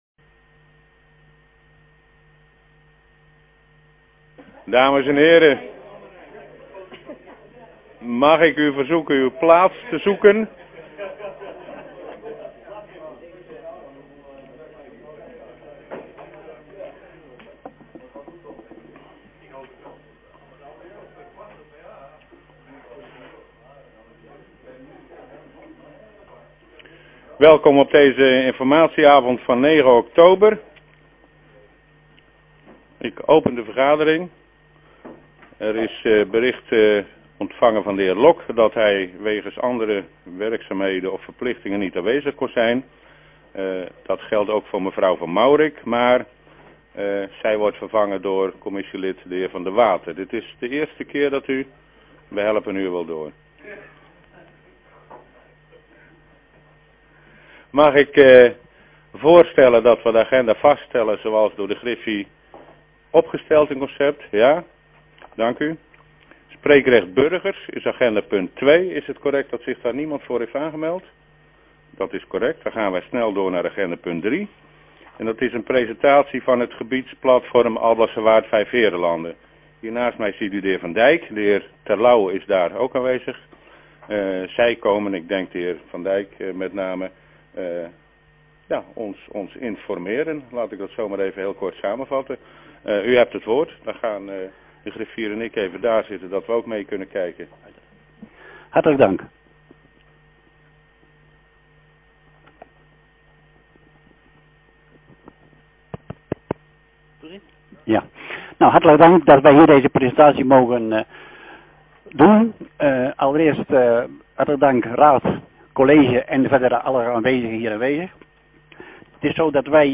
Hoornaar, gemeentehuis - raadzaal